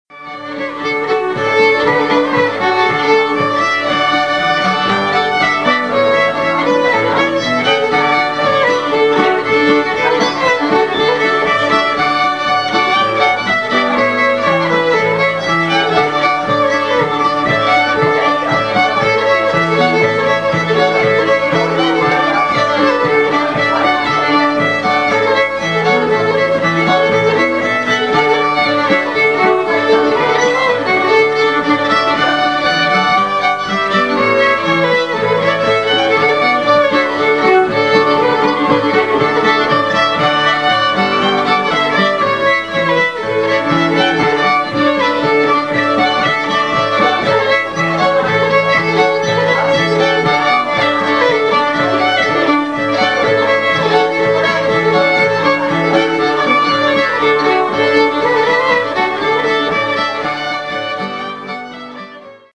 Fiddle
Banjo